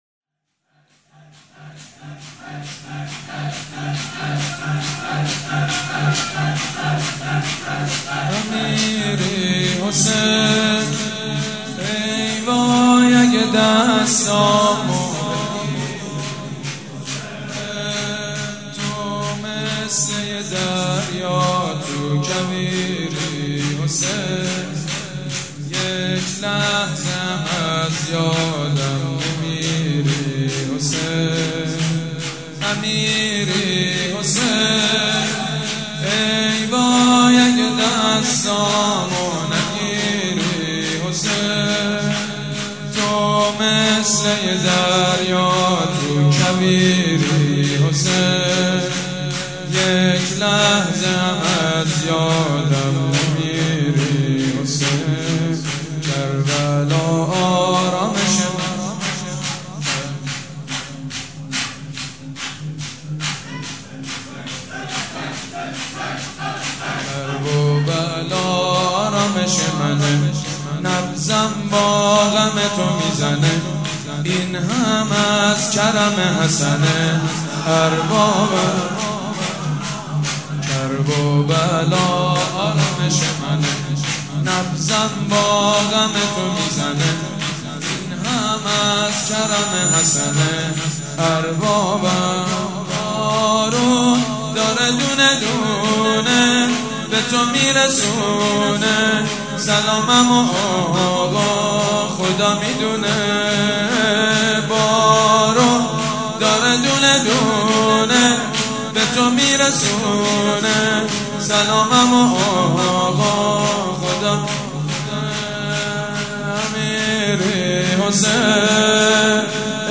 شور
مداح
مراسم عزاداری شب ‌پنجم